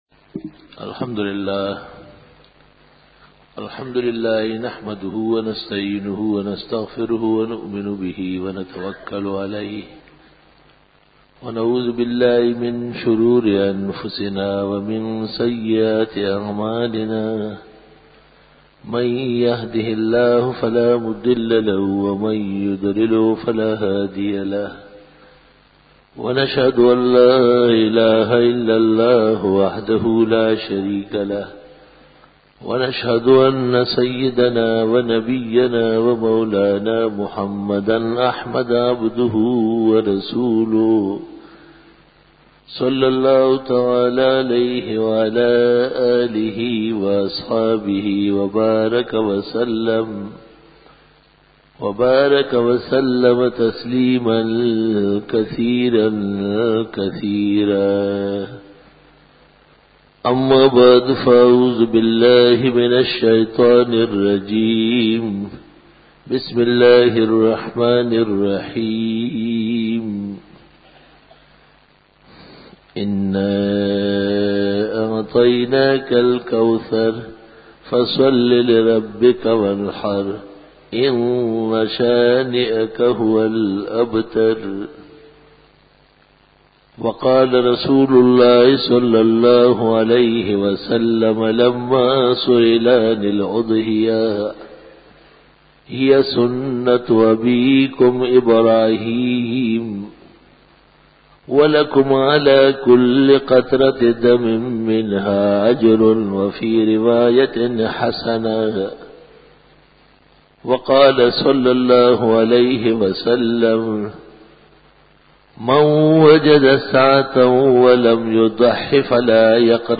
007_Jummah_Bayan_15_Feb_2002